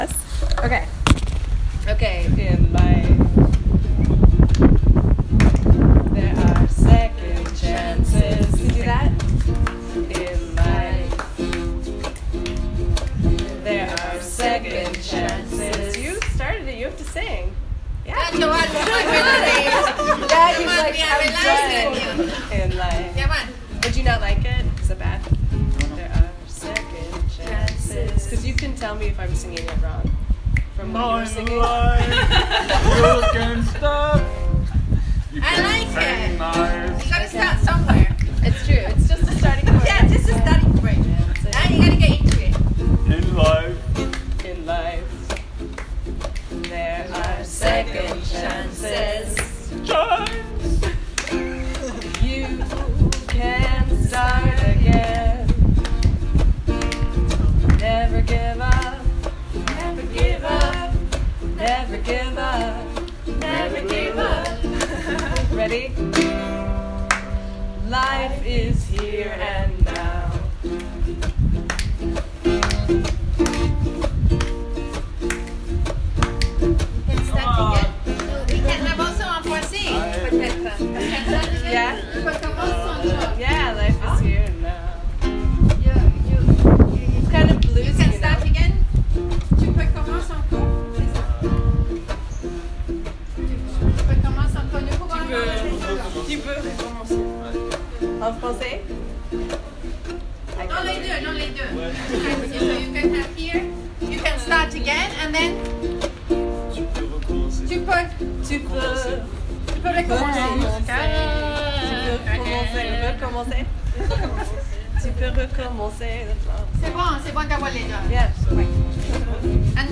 These are recordings from the center that show the process of writing the song:
life-is-here-and-now-writing-the-song-at-the-center02.m4a